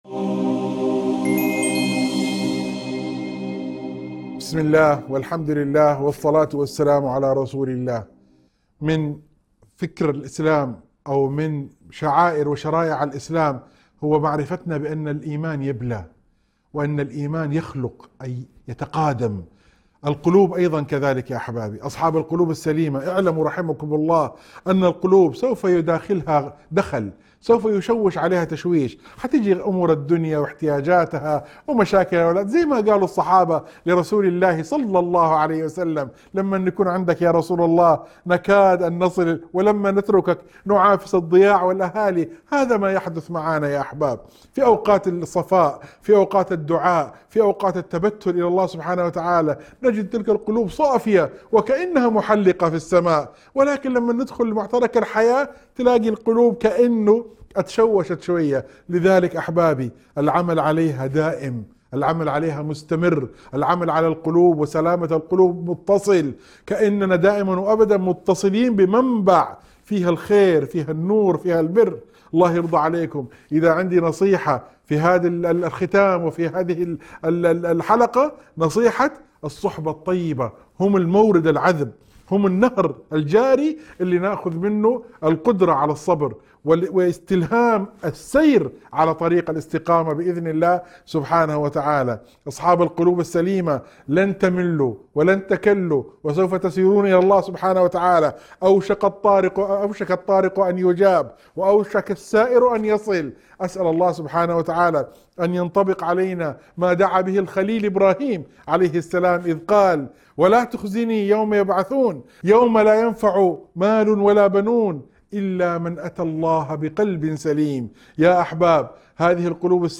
موعظة مؤثرة تركز على سلامة القلب وصلاحه، وتأثير الصحبة الصالحة والأنس بالله في طمأنينة النفس. تذكر بضرورة المحافظة على القلب من تشويش الدنيا وتدعو إلى الرجوع إلى الله بقلب سليم.